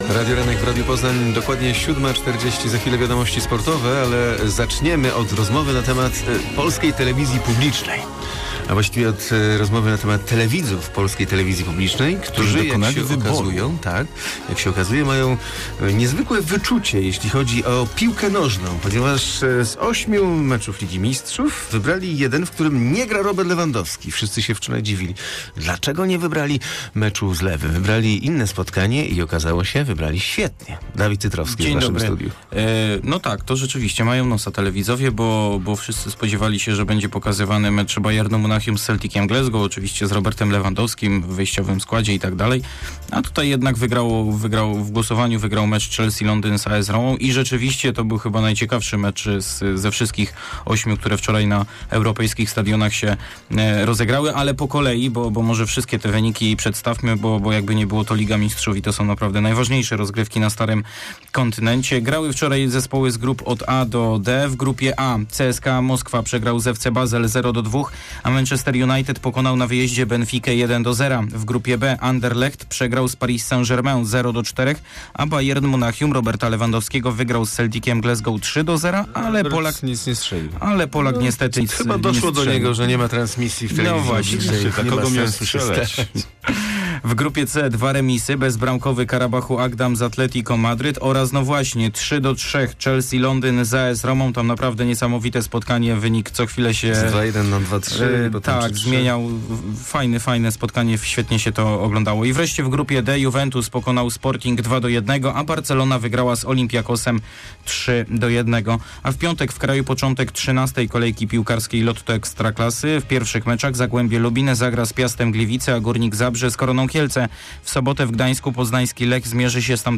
19.10 serwis sportowy godz. 7:40